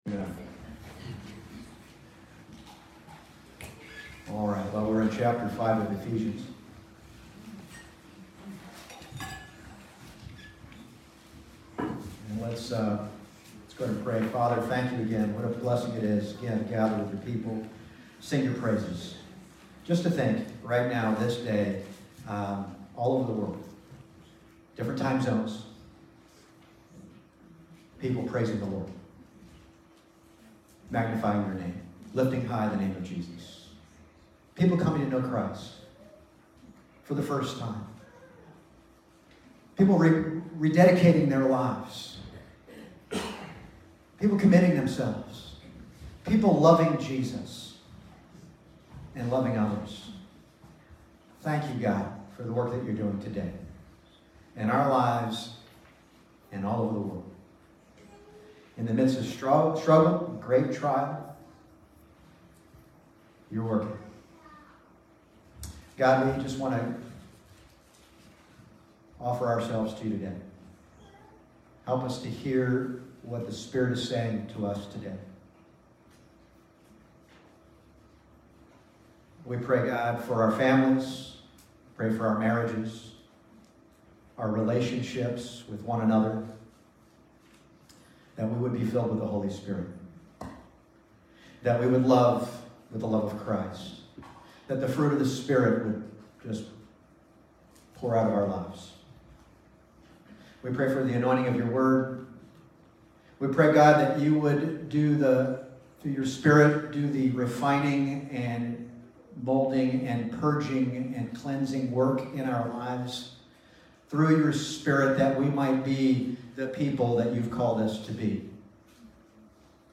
Passage: Ephesians 5:18-33 Service Type: Sunday Morning « Make a Joyful Noise The Spirit-Filled Church